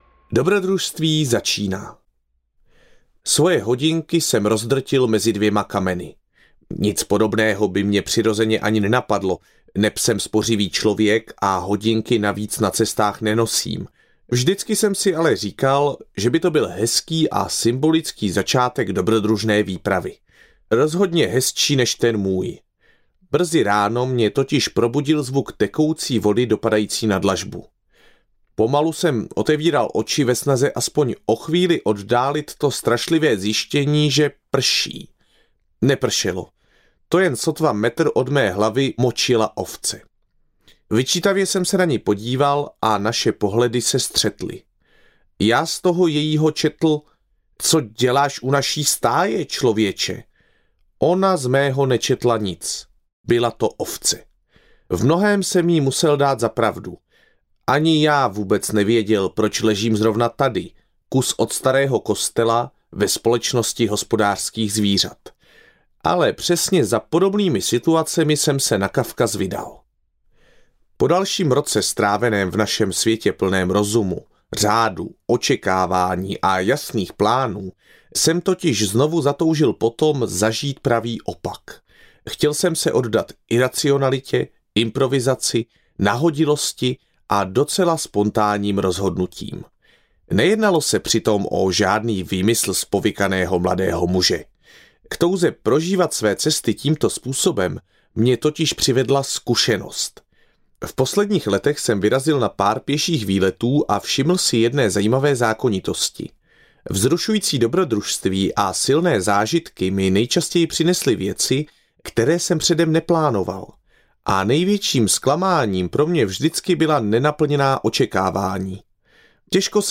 Už nikdy pěšky po Arménii a Gruzii audiokniha
Ukázka z knihy
• InterpretLadislav Zibura